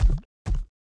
Index of /App/sound/monster/misterious_diseased_spear
foot_1.wav